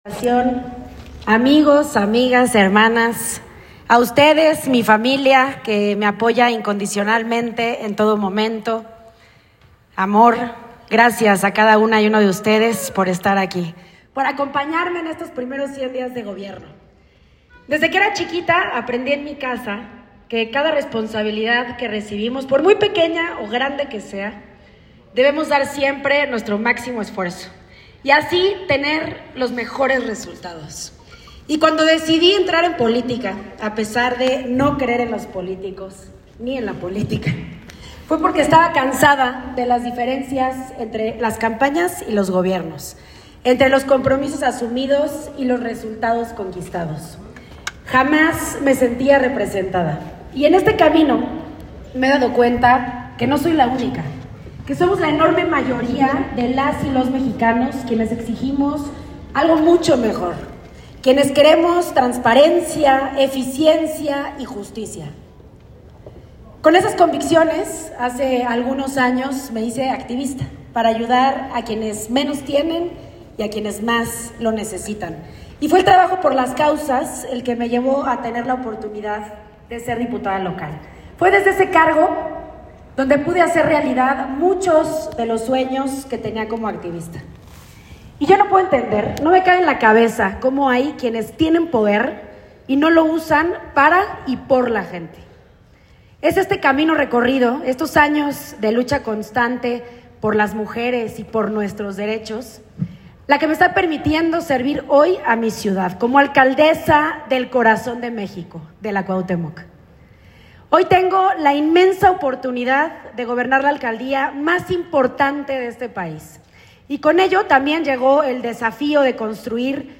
Al cumplirse los primeros 100 días de su administración, la alcaldesa de Cuauhtémoc, Alessandra Rojo de la Vega, ofreció un mensaje en el que informó sobre las acciones emprendidas y los logros alcanzados, destacando avances en seguridad, prevención de la violencia de género, servicios urbanos y recuperación de espacios públicos.